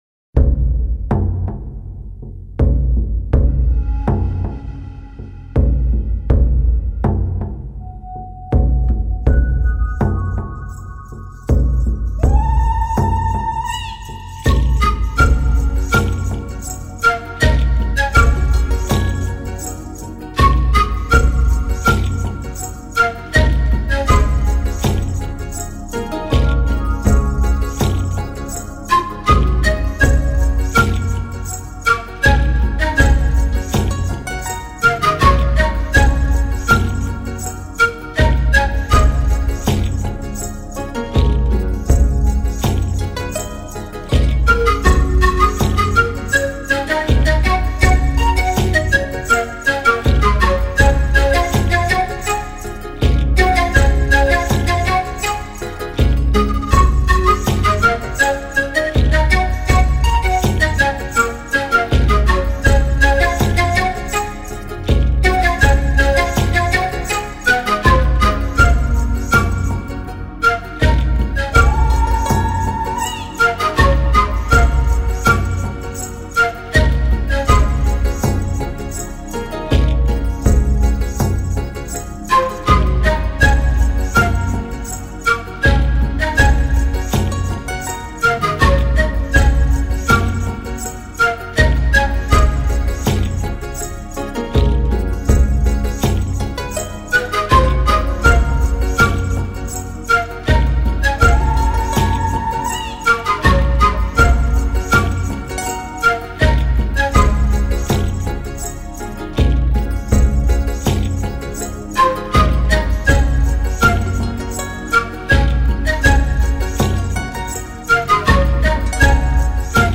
的音乐、玛雅人的祭祀音乐。